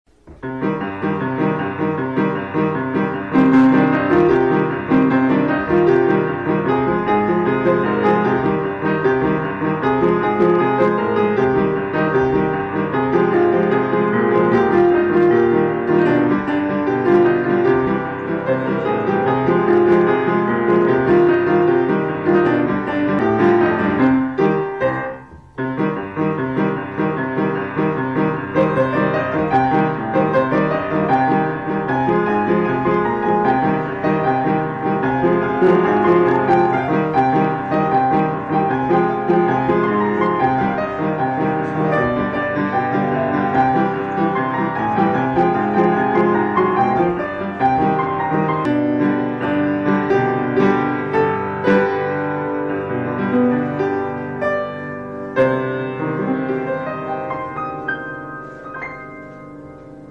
חוץ מכמה פיקשולונים קטנטנים לקראת הסוף הכל טוב!
נשמע ש"קרעת" את המקלדת.
זה פסנתר.
יכול להיות ששמתי באמת יותר מידי קרוב ת'אמפי.